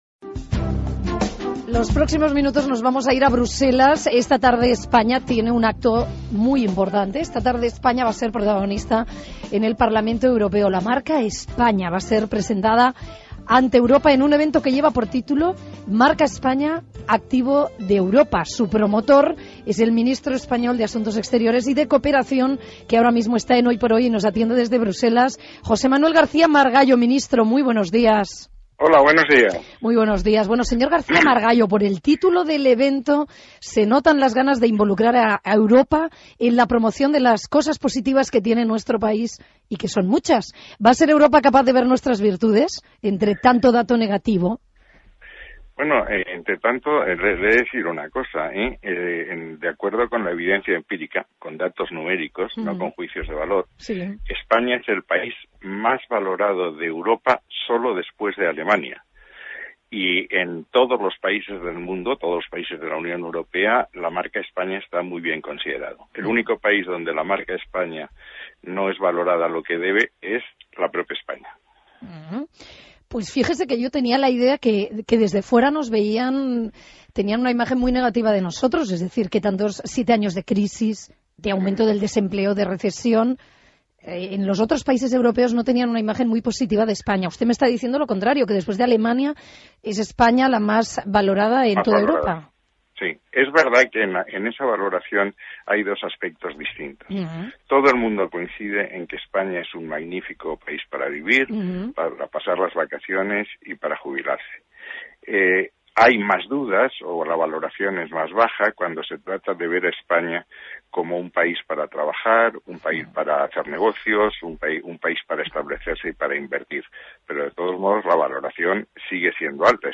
Entrevista a José Manuel García-Margallo en la cadena SER
El ministro de Asuntos Exteriores y de Cooperación interviene en el programa 'Hoy por hoy'
ENTREVISTADO POR GEMMA NIERGA